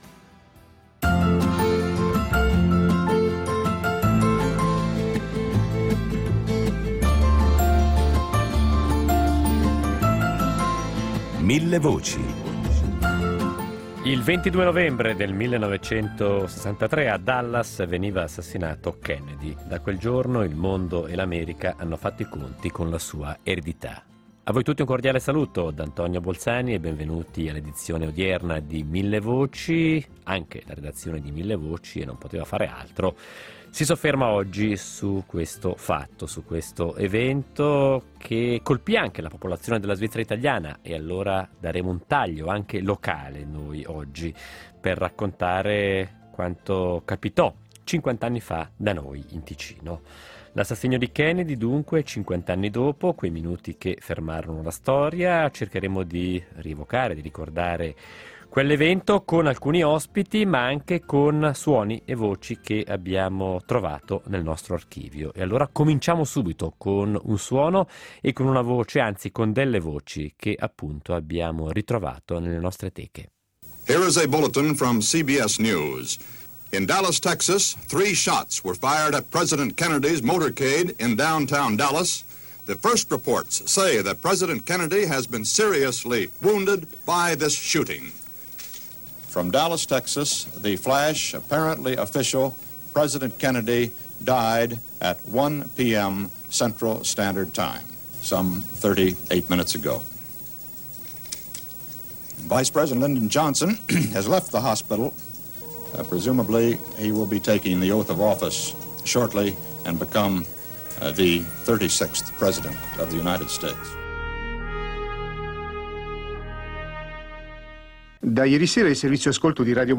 con l’aiuto di suoni e voci dell’archivio della RSI